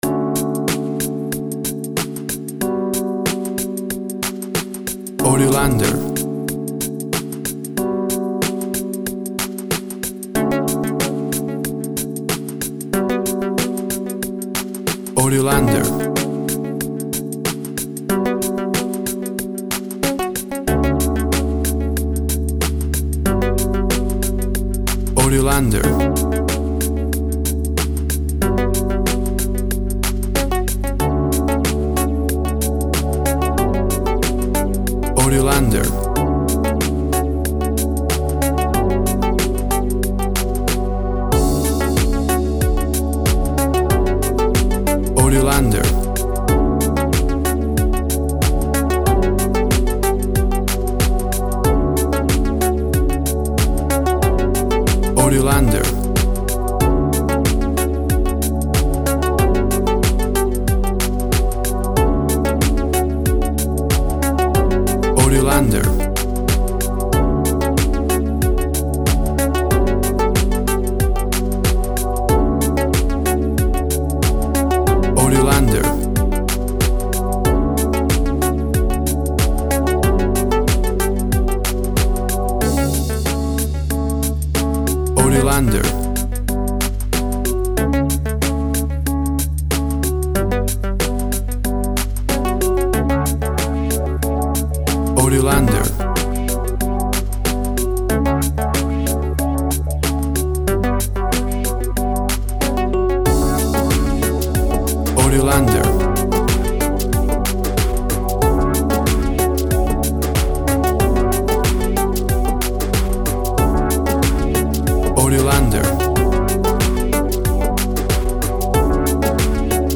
WAV Sample Rate 16-Bit Stereo, 44.1 kHz
Tempo (BPM) 93